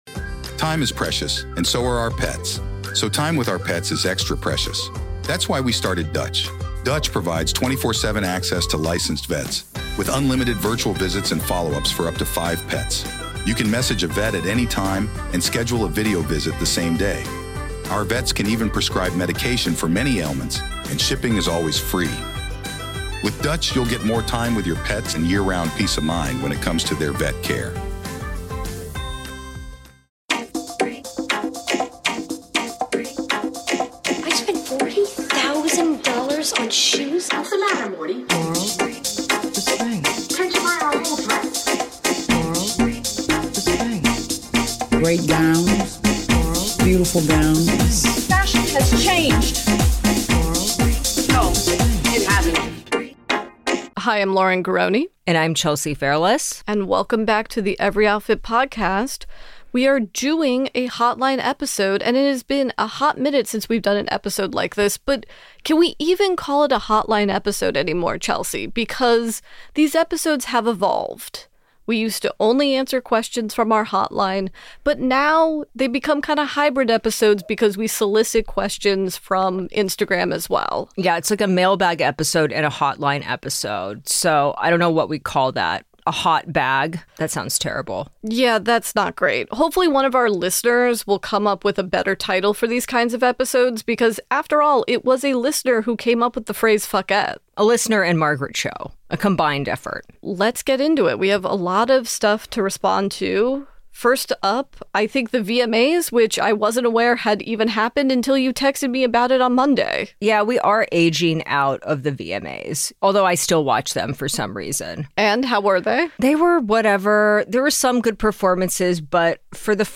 We’re back with another hotline/mailbag…a hotbag (?!) episode. Topics discussed include: VMA fashion, the controversial Wuthering Heights trailer, most worthwhile repo babies, our death row meals, and so much more!